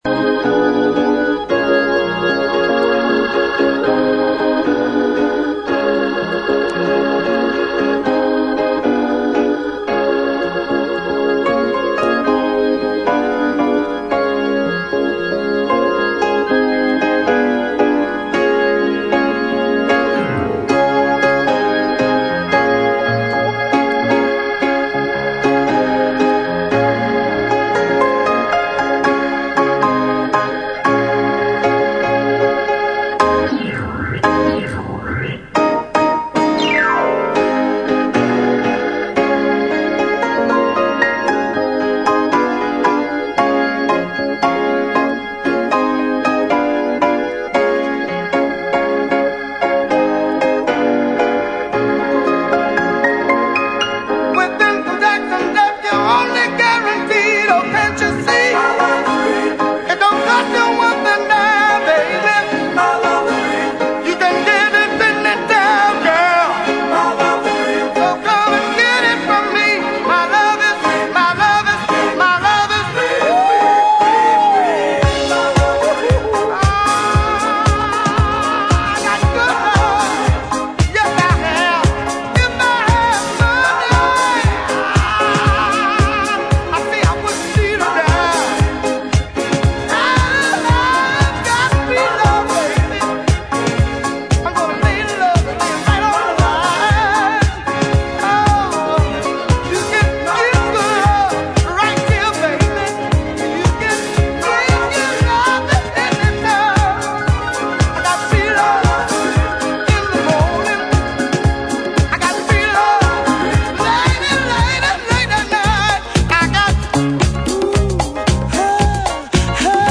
ノンビートのピアノ演奏で幕を開ける
ジャンル(スタイル) DISCO / SOUL / FUNK / RE-EDIT